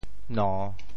“驽”字用潮州话怎么说？
驽（駑） 部首拼音 部首 马 总笔划 8 部外笔划 5 普通话 nú 潮州发音 潮州 no3 文 中文解释 驽 <名> (形声。